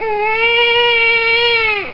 Another Lil Baby Sound Effect